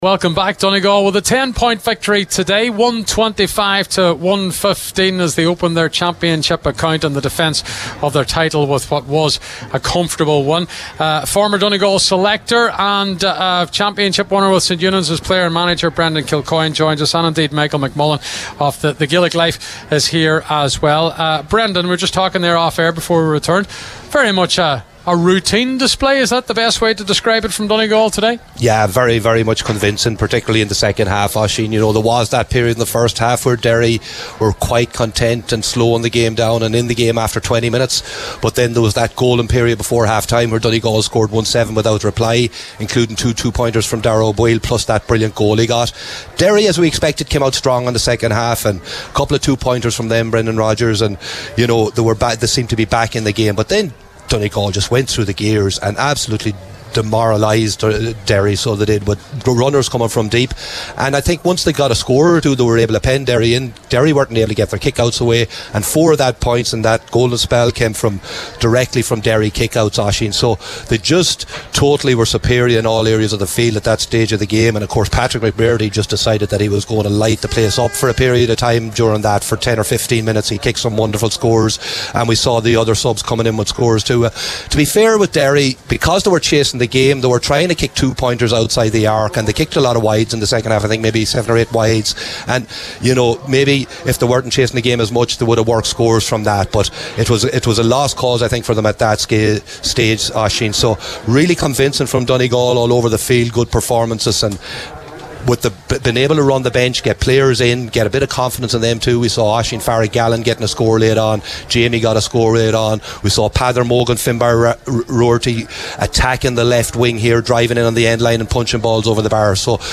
Post-Match Reaction
were live at full time for Highland Radio Sunday Sport…